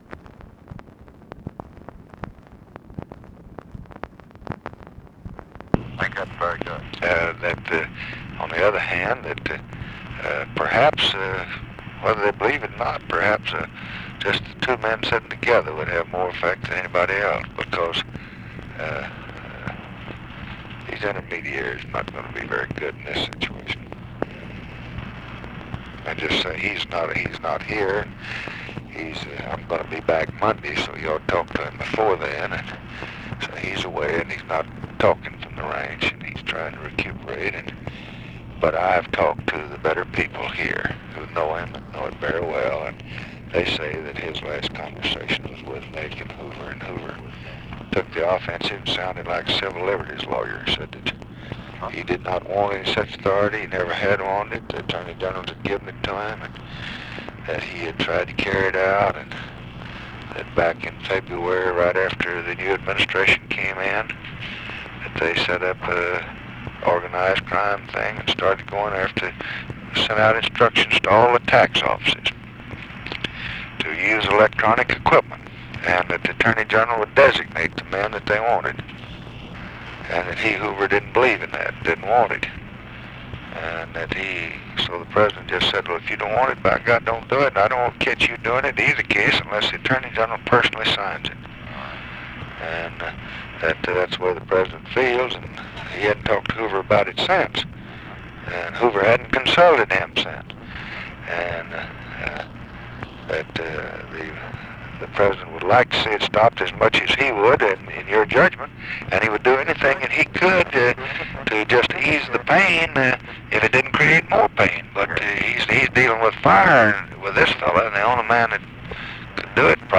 Conversation with CLARK CLIFFORD, December 24, 1966
Secret White House Tapes